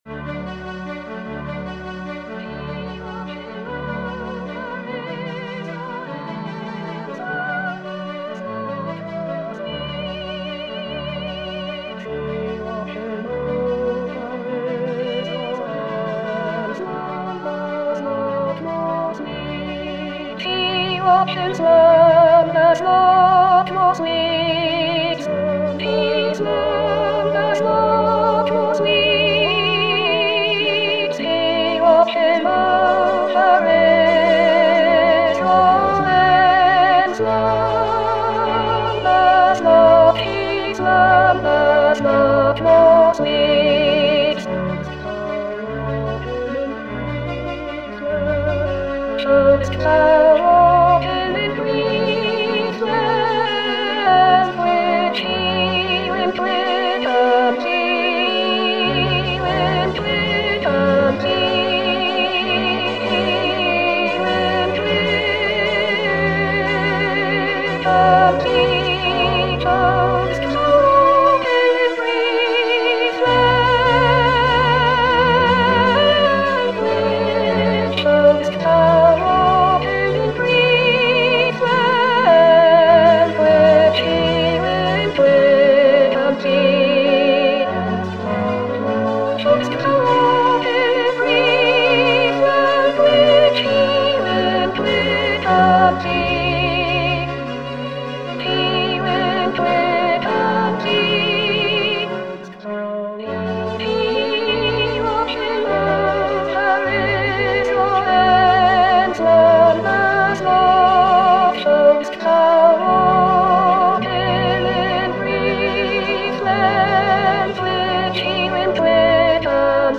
Soprano Soprano 2